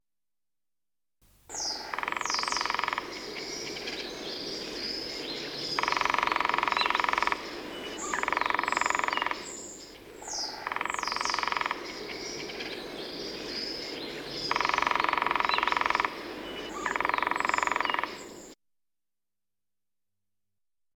Specht
specht.m4a